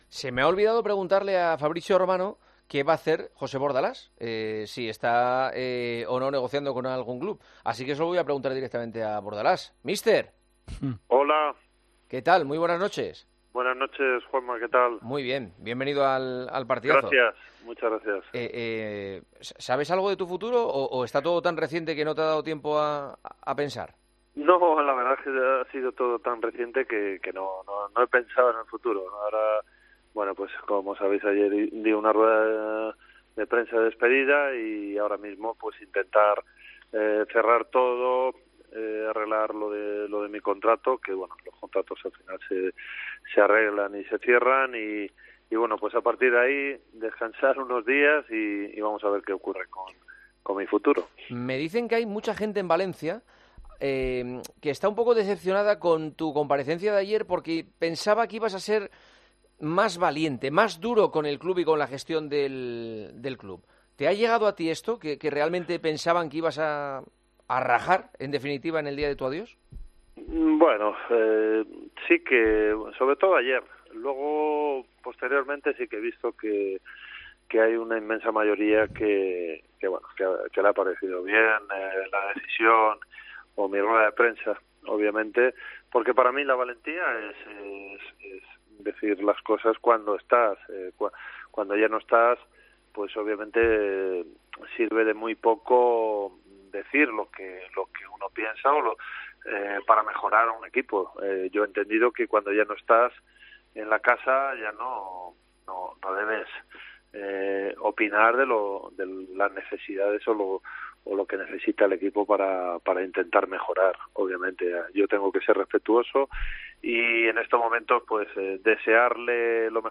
Además, y a pesar que el técnico solo lleva unas horas como entrenador libre, Bordalás le ha desvelado a Juanma Castaño que ya tiene una oferta para entrenar.